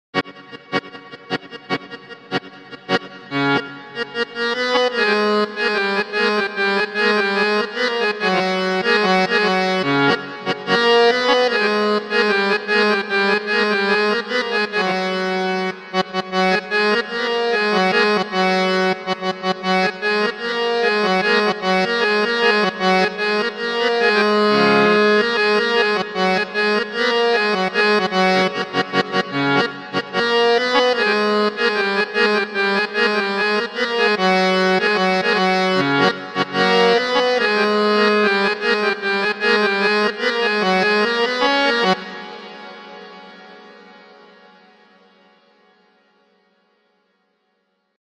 The British harmonium was a reed organ that would require a chair to utilize the foot pedals pump air.
AUDIO CLIP: Harmonium
harmonium.mp3